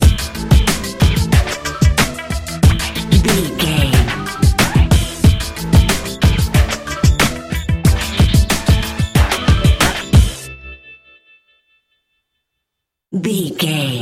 Aeolian/Minor
drum machine
synthesiser
percussion
neo soul
acid jazz
energetic
bouncy